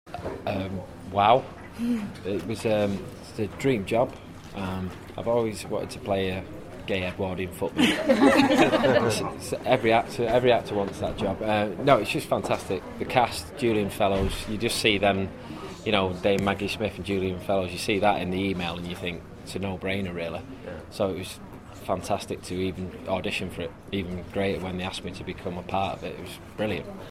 Then small round table interviews with many of the actors involved.